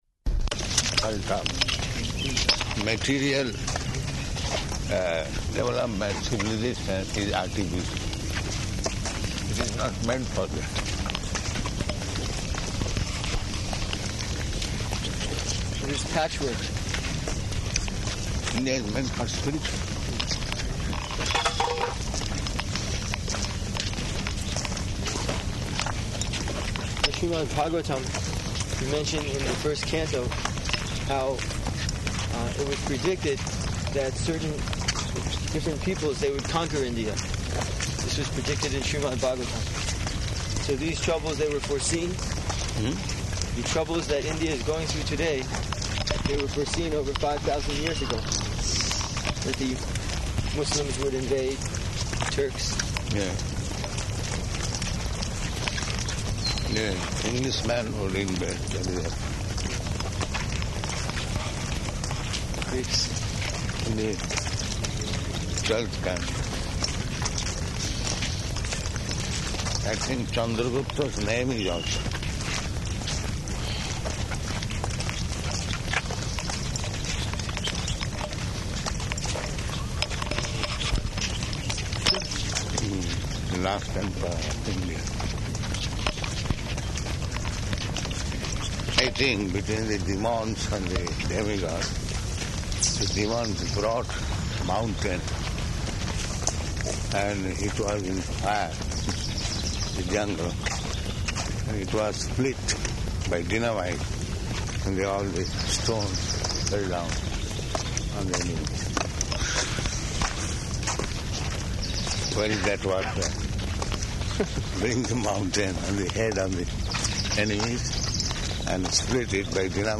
Morning Walk --:-- --:-- Type: Walk Dated: June 14th 1976 Location: Detroit Audio file: 760614MW.DET.mp3 Prabhupāda: ...point out material development of civilization is artificial.